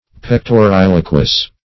Pectoriloquous \Pec`to*ril"o*quous\, a.